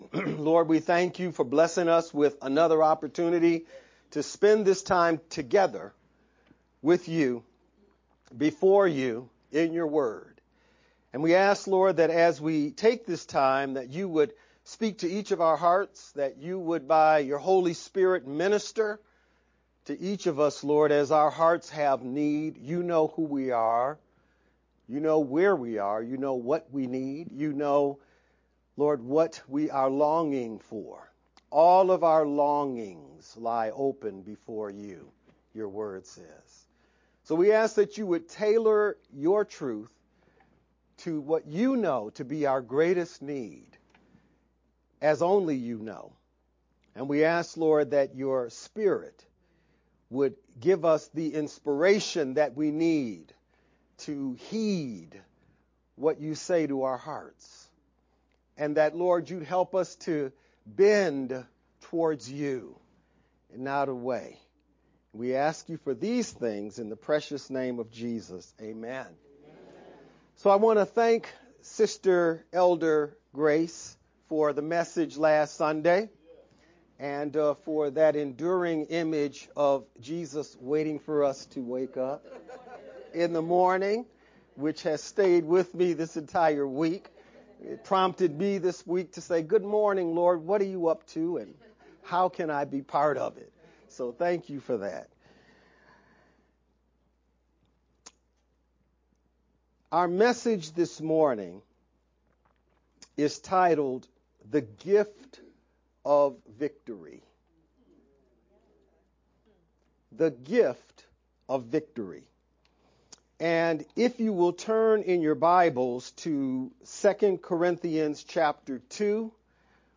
VBCC-edited-Dec-7th-sermon-only_Converted-CD.mp3